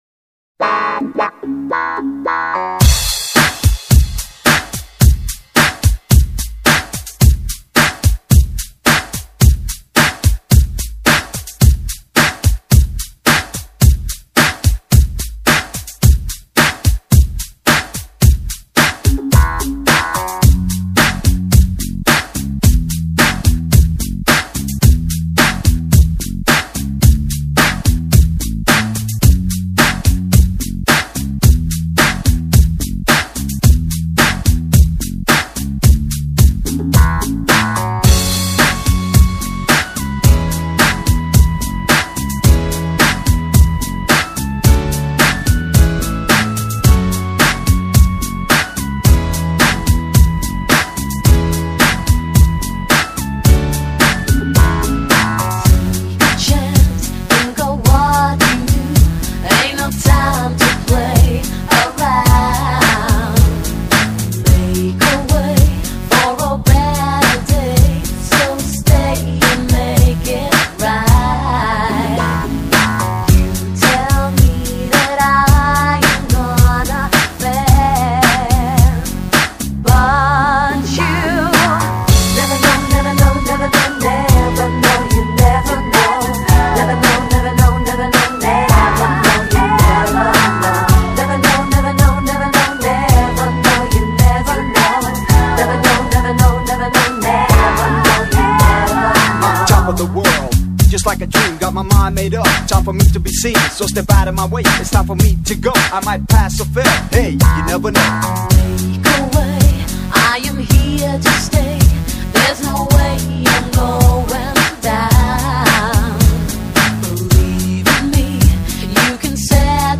[24/11/2008]在寒冷的冬天让各位动起来~~~~（极舒服慢摇） 激动社区，陪你一起慢慢变老！